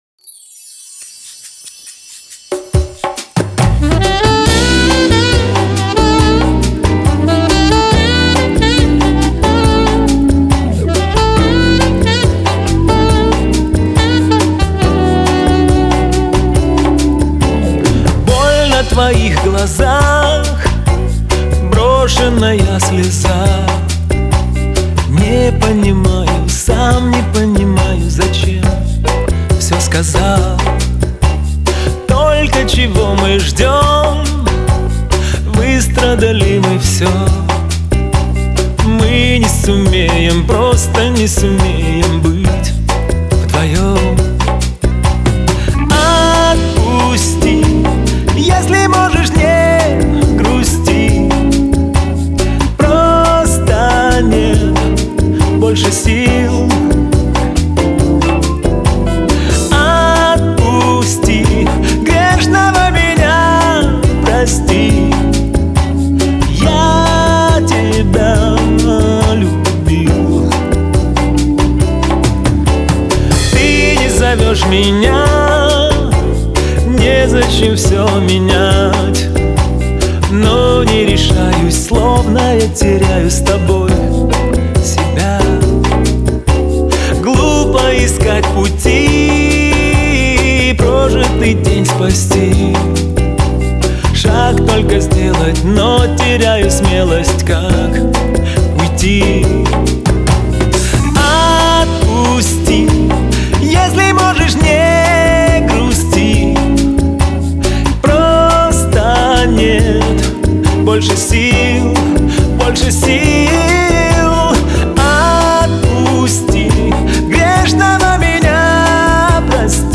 это эмоциональная песня в жанре поп